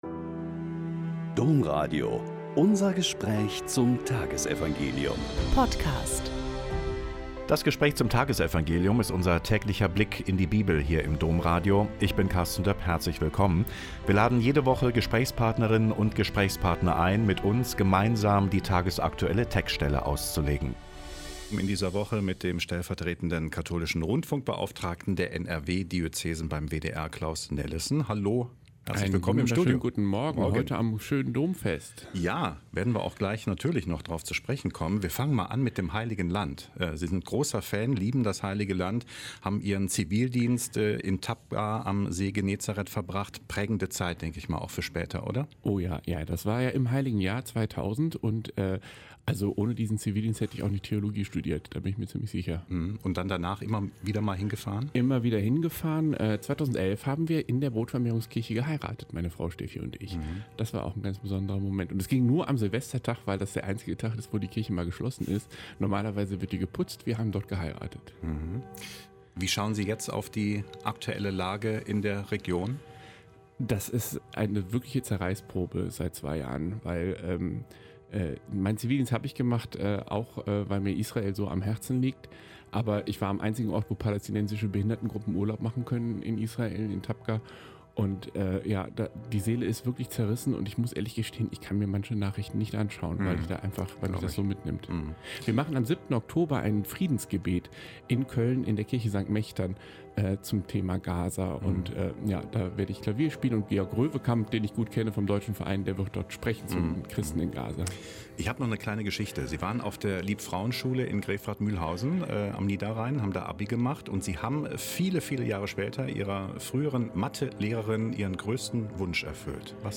Mt 16,13-19 - Gespräch